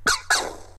Cri de Feunnec dans Pokémon X et Y.